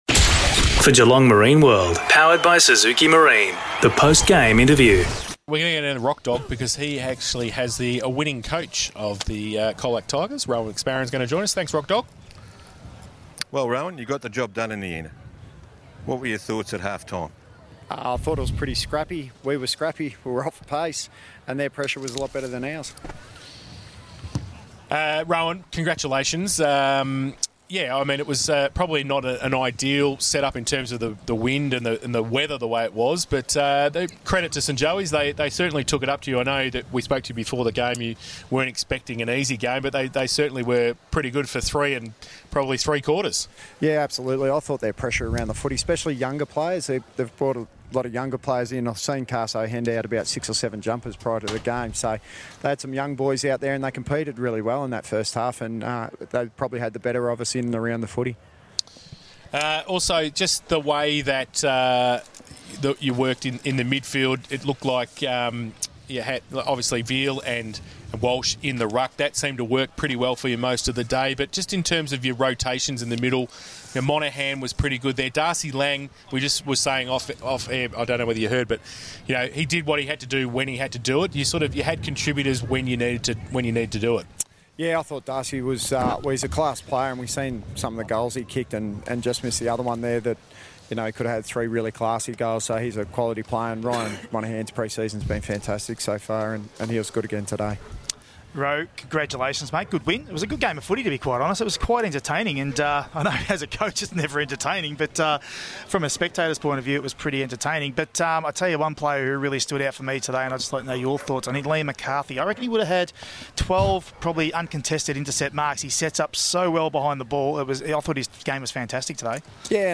2022 - GFL ROUND 1 - ST JOSEPH'S vs. COLAC: Post-match Interview